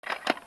list_click.ogg